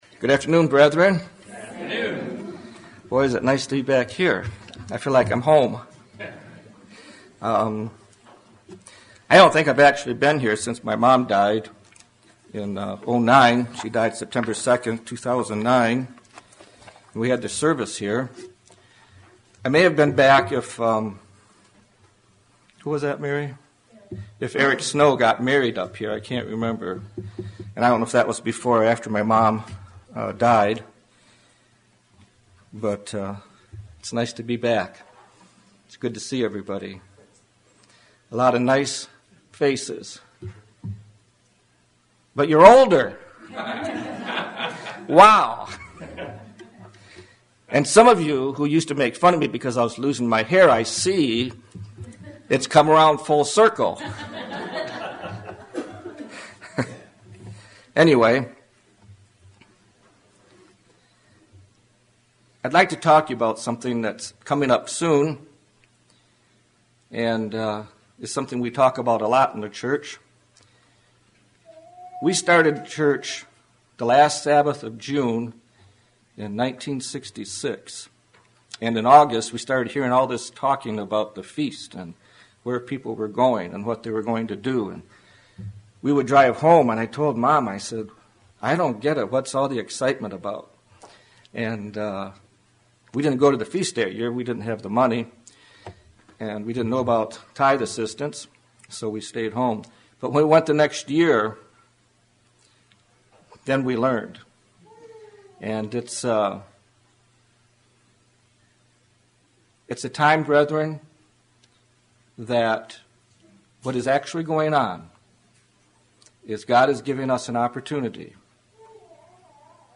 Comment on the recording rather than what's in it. Given in Flint, MI